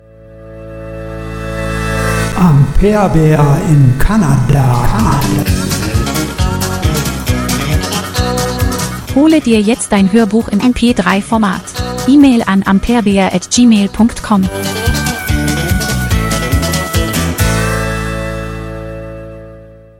oder als Hörbuch im MP3-Format